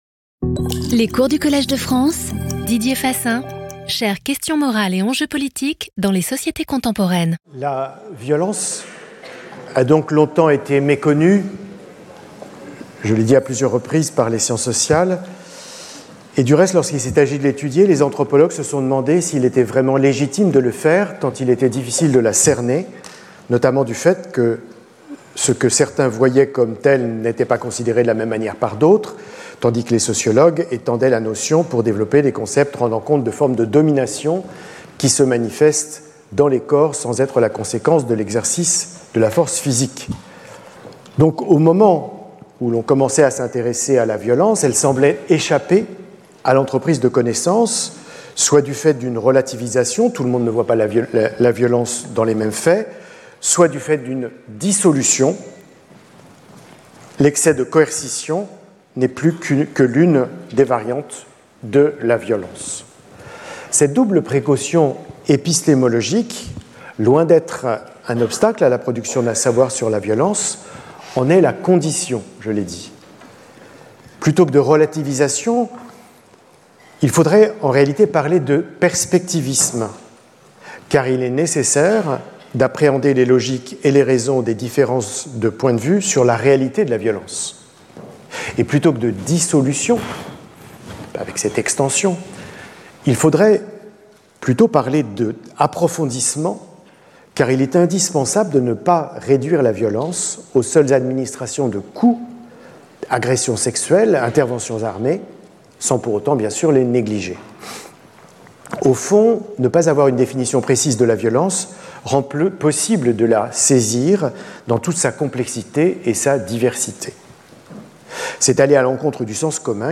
Speaker(s) Didier Fassin Professor at the Collège de France
Lecture